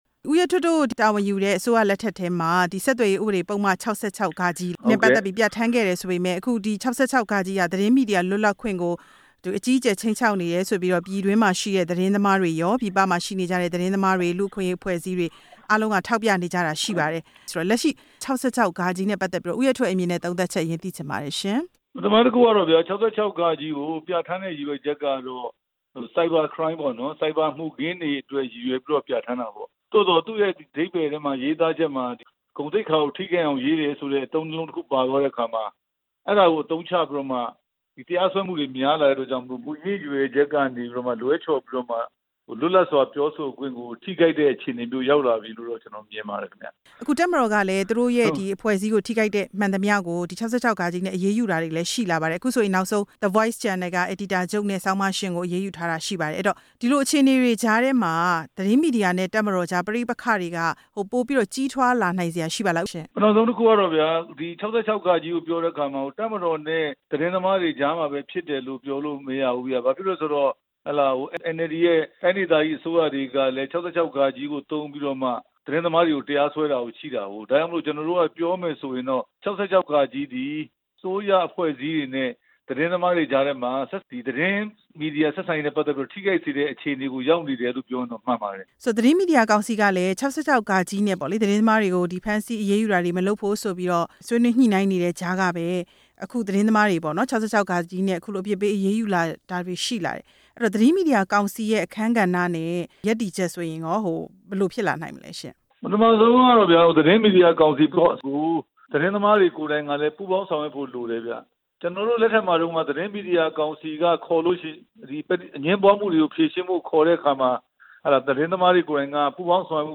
ဆက်သွယ်ရေးဥပဒေနဲ့ ပတ်သက်လို့ ဝန်ကြီးဟောင်း ဦးရဲထွဋ်နဲ့ မေးမြန်းချက်